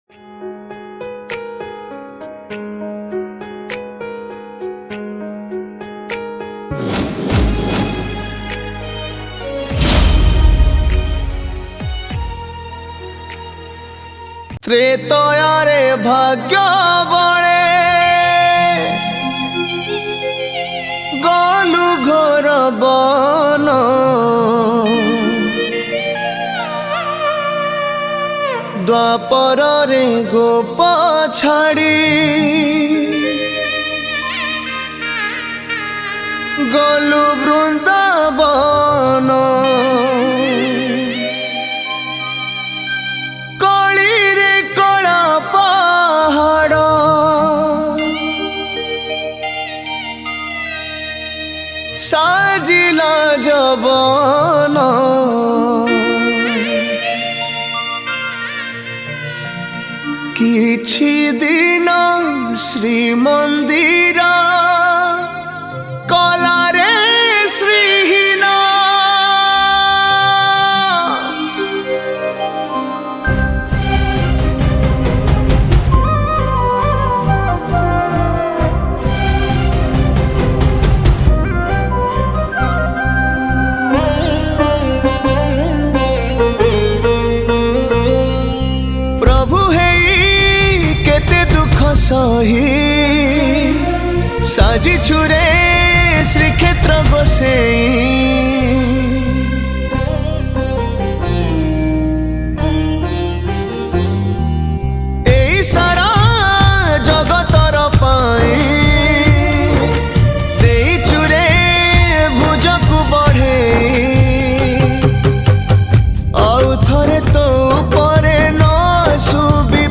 Dholak
Tabla